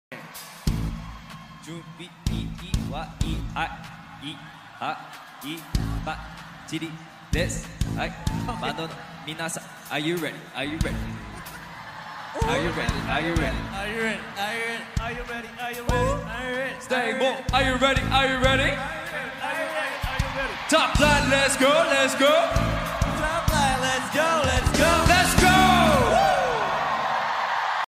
Before doing the topline, do a baby rap to ask if you are ready